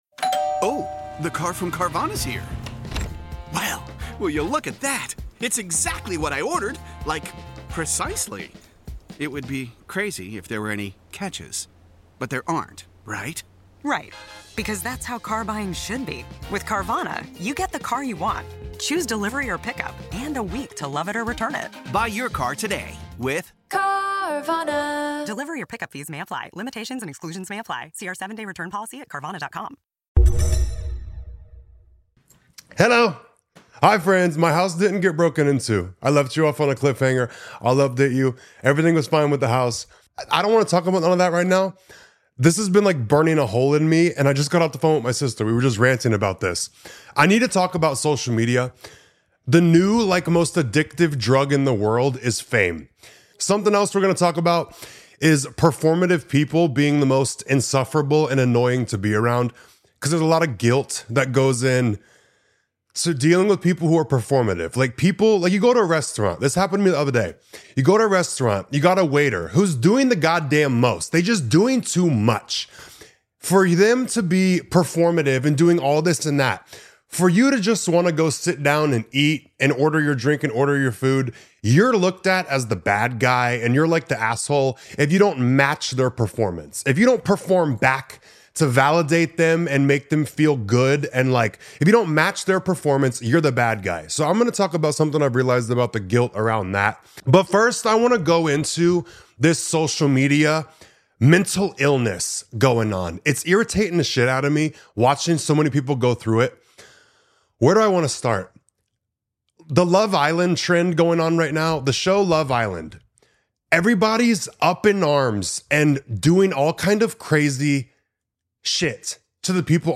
This is a rant from my soul.